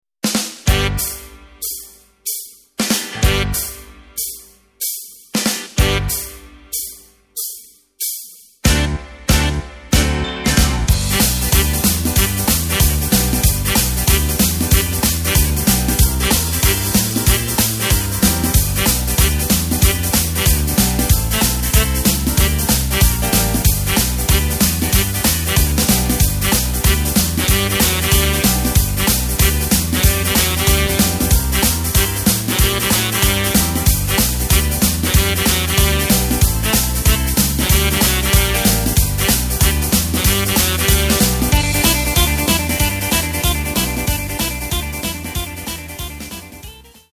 Takt:          4/4
Tempo:         188.00
Tonart:            G
Playback mp3 Demo